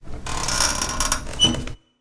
crowbar.ogg.mp3